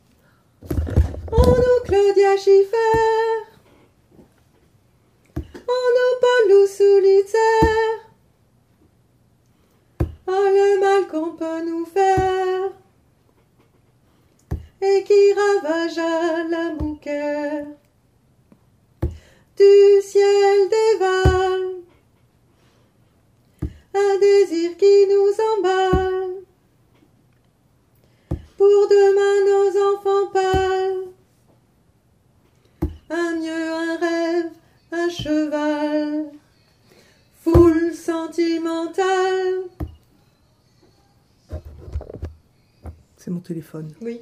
Couplet 3 alti 1